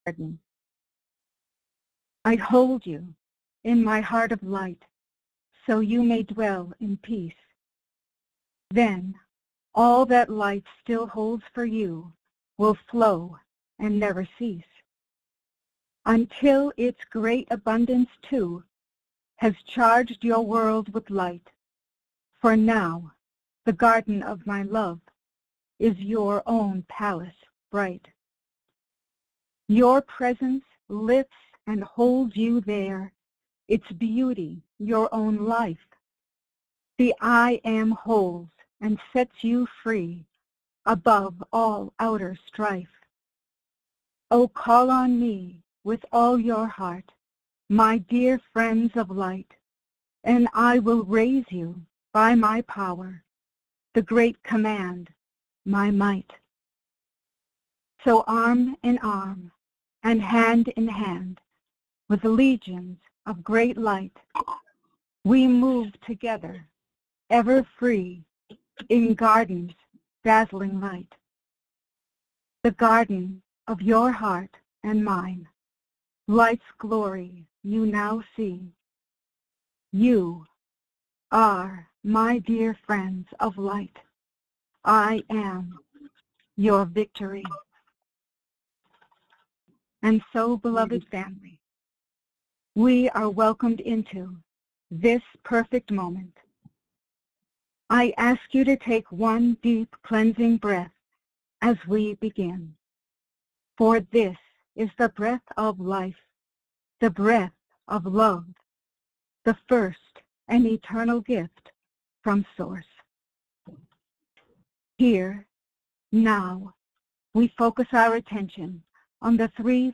Join in group meditation